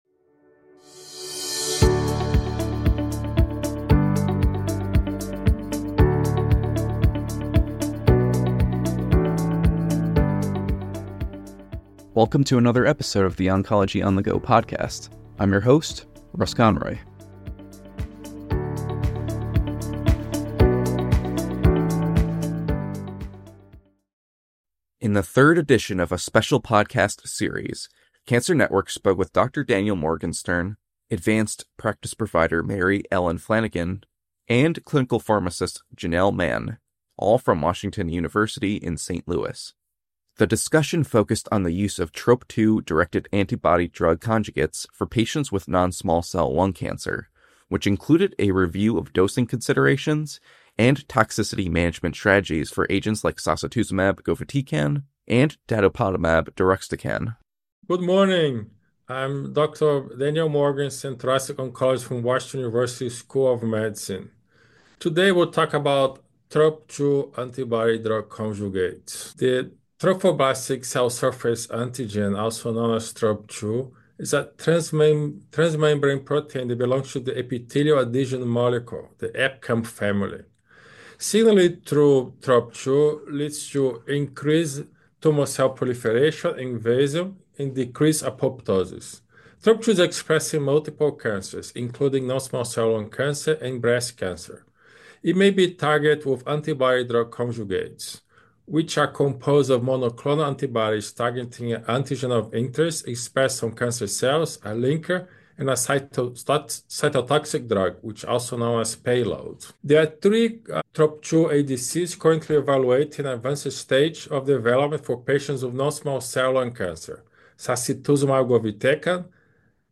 Experts from Washington University in St. Louis discuss dosing considerations and toxicity management strategies for TROP2-targeted ADCs in NSCLC.